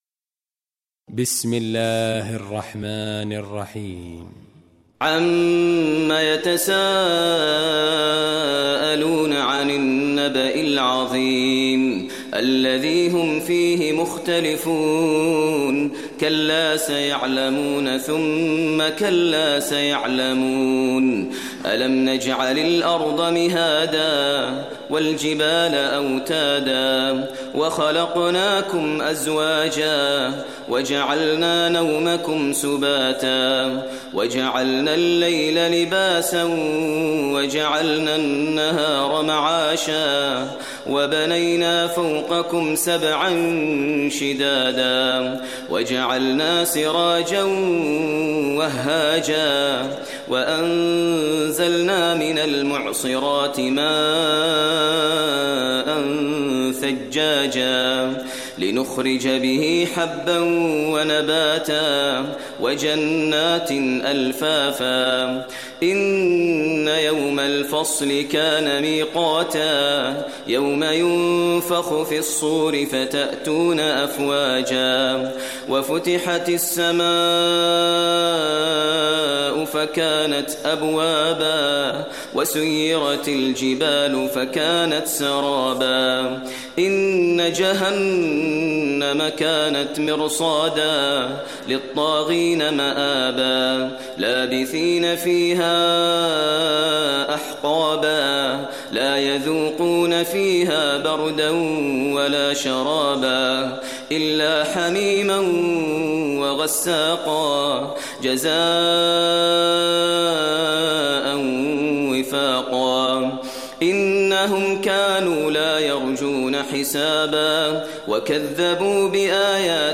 Surah Naba Recitation by Maher al Mueaqly
Surah An Naba, listen or play online mp3 tilawat / recitation in Arabic recited by Imam e Kaaba Sheikh Maher al Mueaqly.